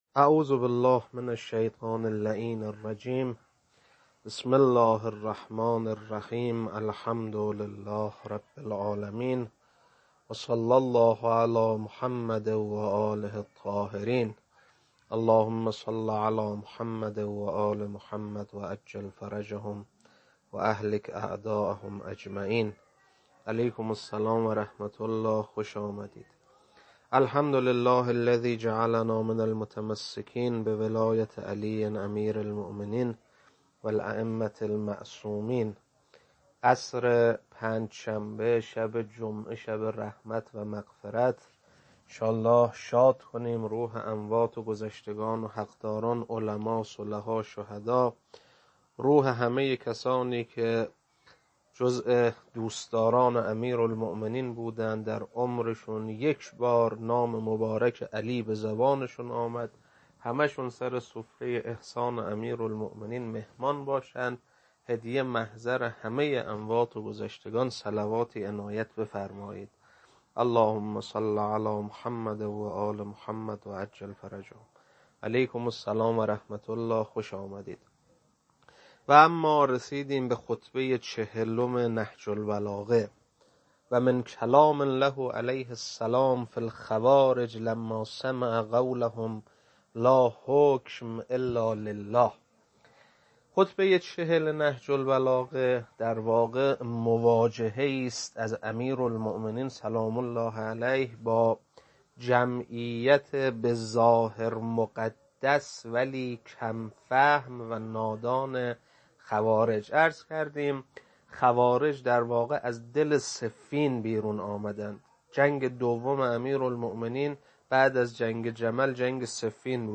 خطبه 40.mp3